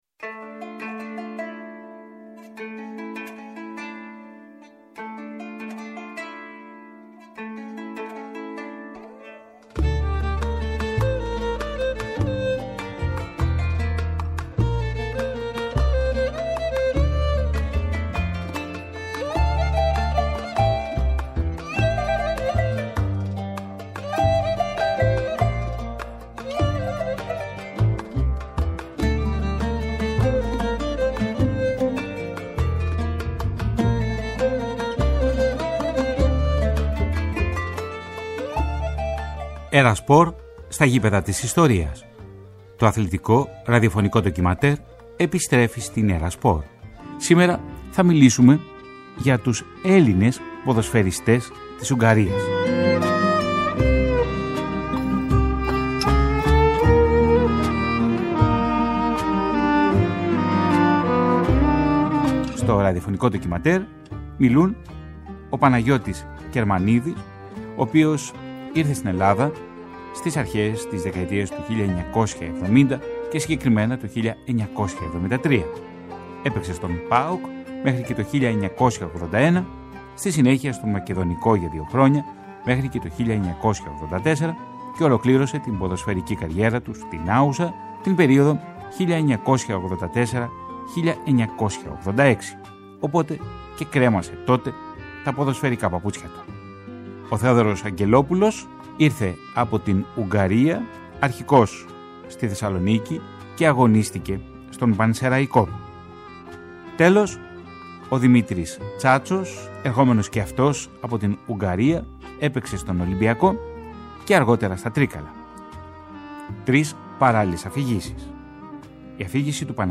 ραδιοφωνικού ντοκιμαντέρ